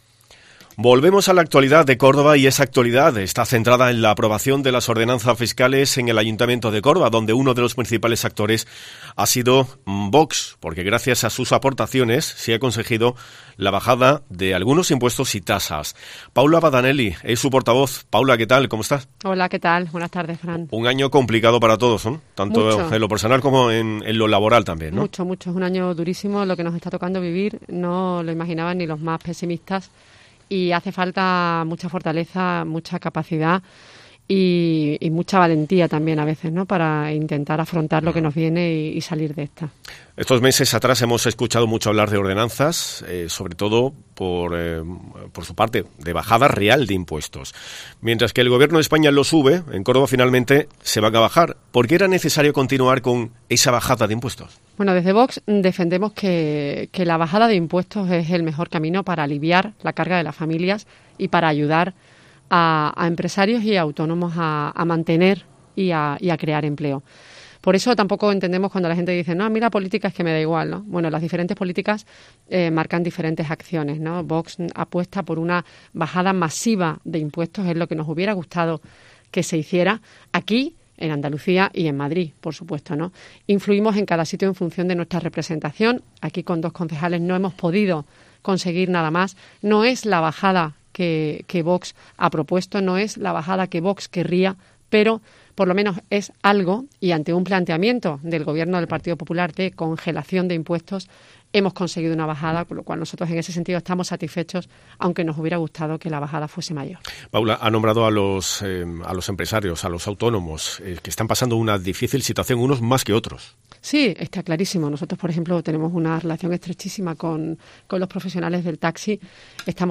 Por los micrófonos de COPE ha pasado esta mañana la portavoz de VOX en el Ayuntamiento de Córdoba, Paula Badanelli, quien ha abordado la actualidad municipal en materia económica realizando un balance sobre el proyecto inicial de ordenanzas fiscales aprobado el pasado jueves.